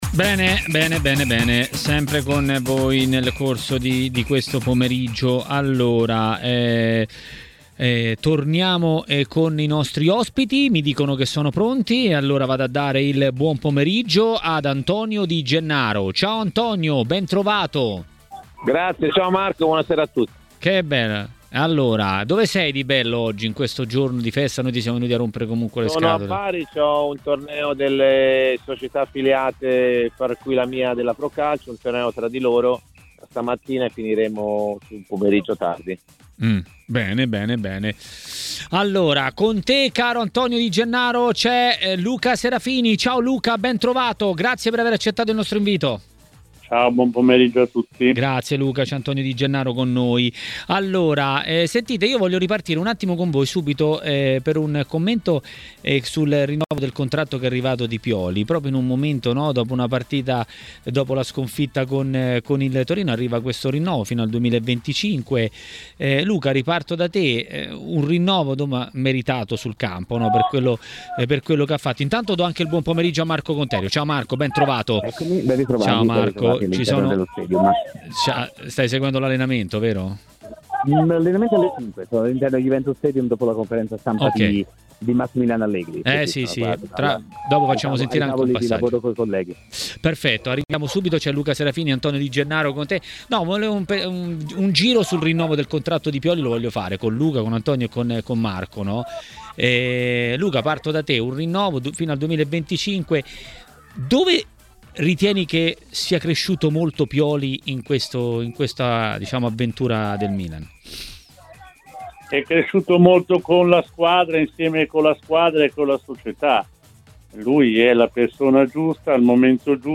Ospiti